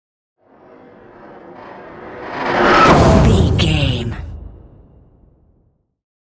Dramatic whoosh to hit trailer
Sound Effects
Fast paced
In-crescendo
Atonal
dark
intense
tension
woosh to hit